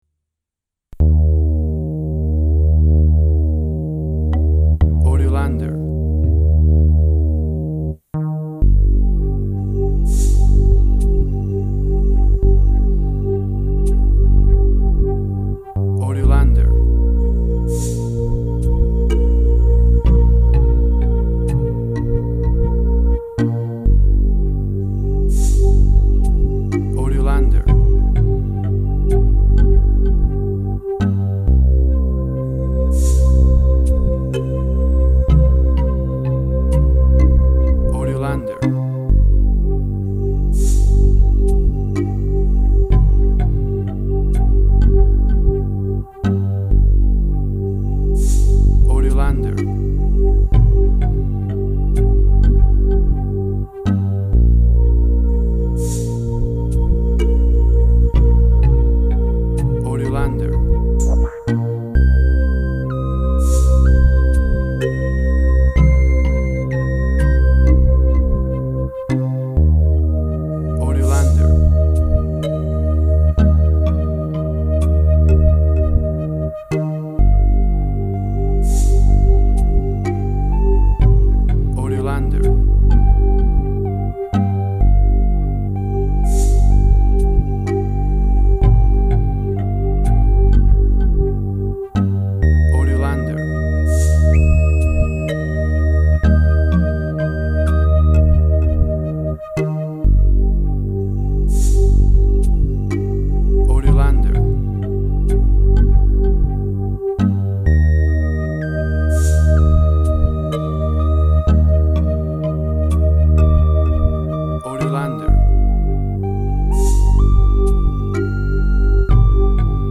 Tempo (BPM): 63